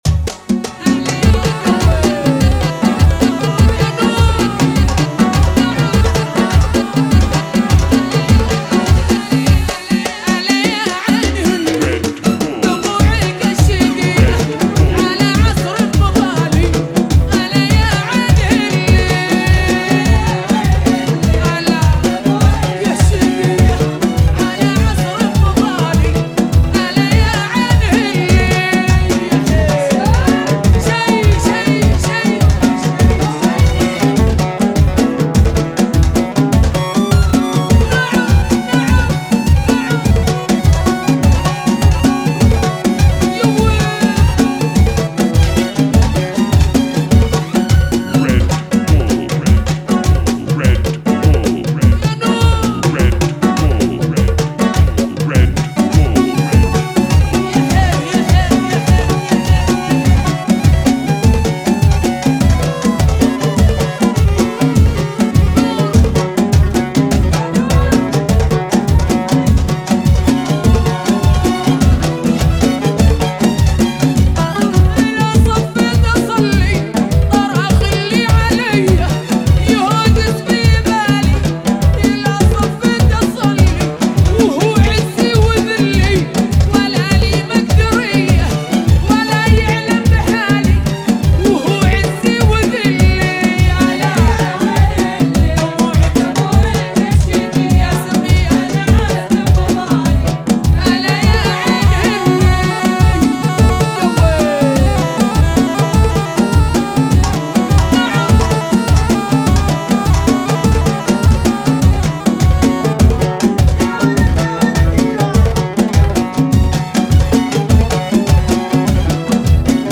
[ 102 bpm ]